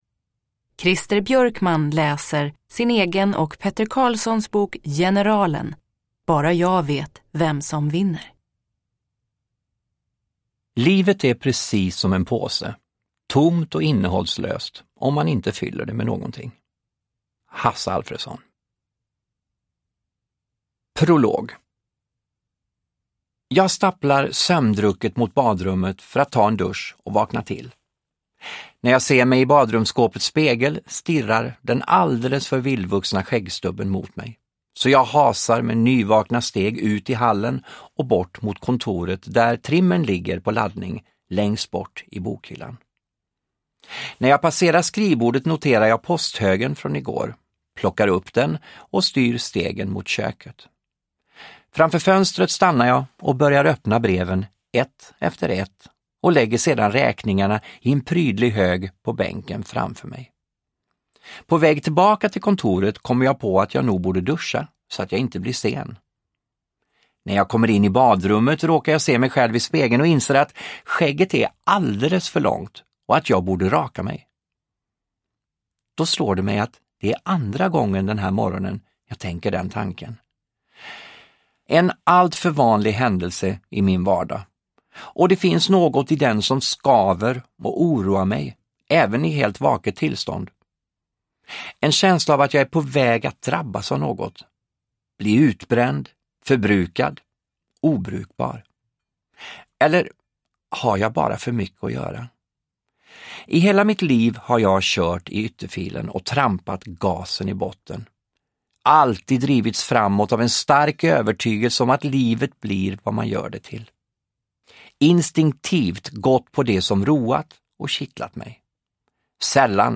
Uppläsare: Christer Björkman
Ljudbok